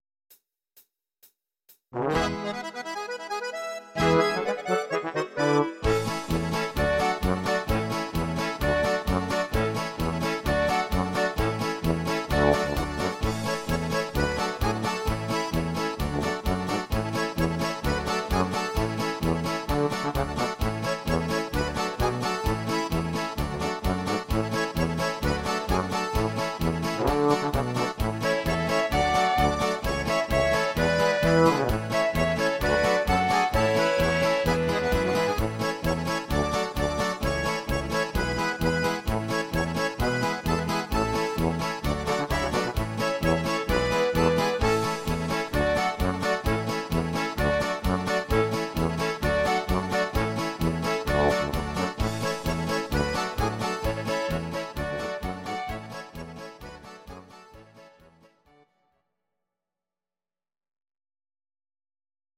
These are MP3 versions of our MIDI file catalogue.
Please note: no vocals and no karaoke included.
Oberkrainer Sound